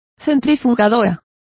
Complete with pronunciation of the translation of centrifuges.